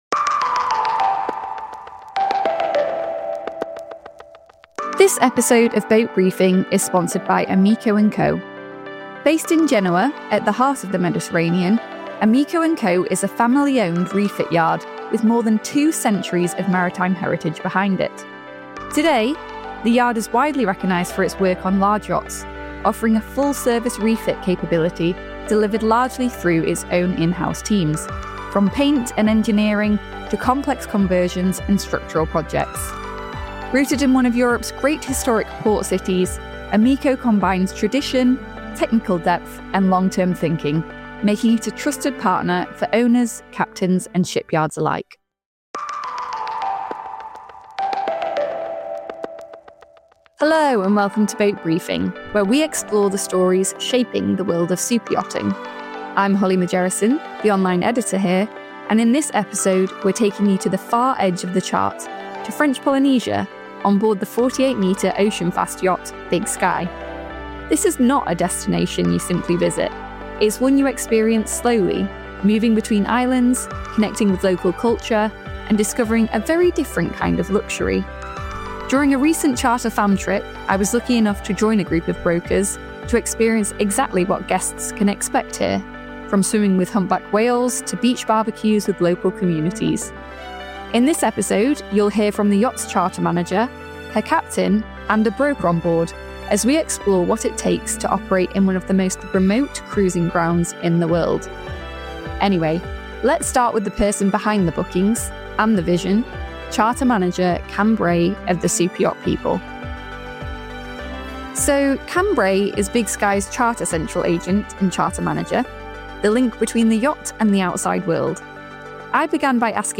Recorded in the Society Islands, this is a behind-the-scenes look at chartering far beyond the Med.